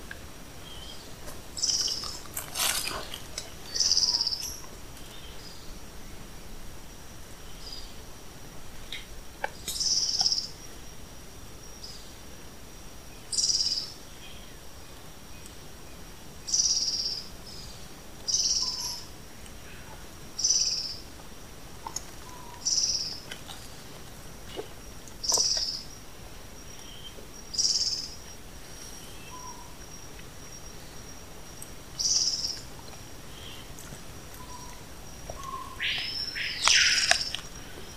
Scalloped Antbird (Myrmoderus ruficauda)
Detailed location: Estação Ecológica de Murici
Condition: Wild
Certainty: Recorded vocal
Formigueiro-de-cauda-ruiva.mp3